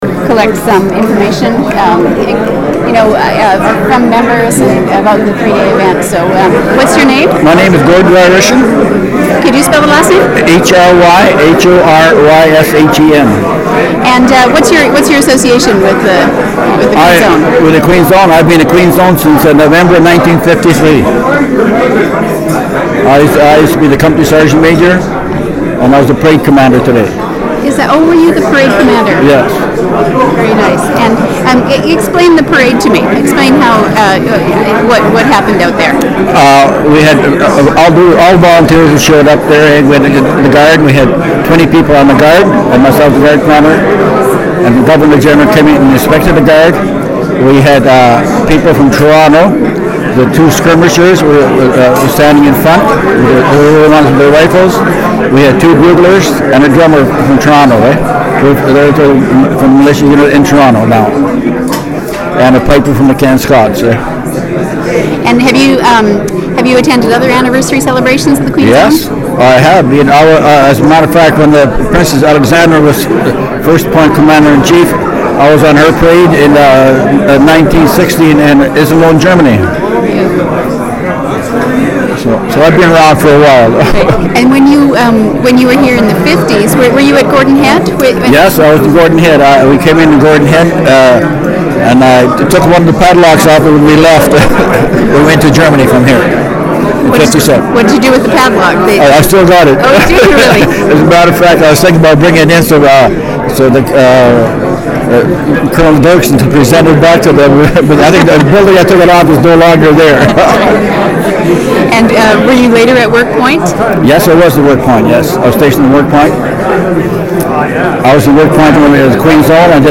• Interview took place during the Queen's Own Rifles of Canada Vancouver Island Branch 150th Anniversary Celebration.
• Canadian Military Oral History Collection